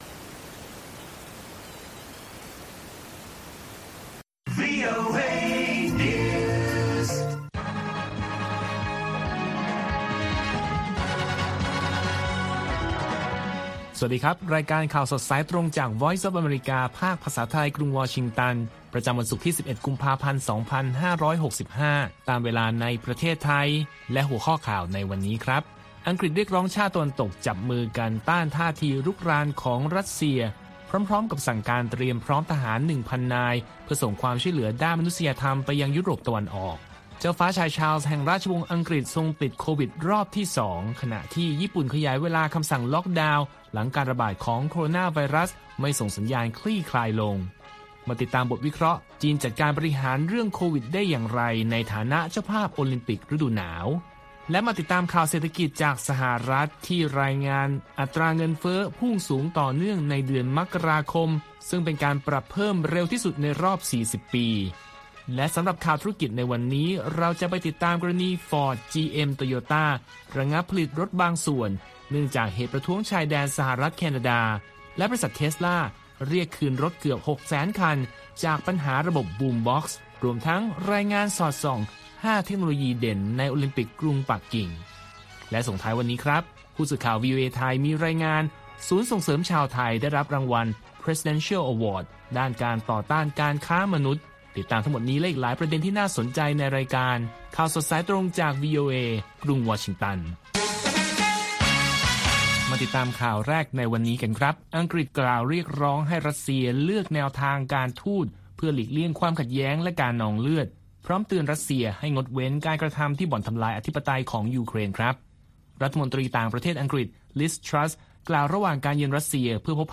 ข่าวสดสายตรงจากวีโอเอ ภาคภาษาไทย ประจำวันศุกร์ที่ 11 กุมภาพันธ์ 2565 ตามเวลาประเทศไทย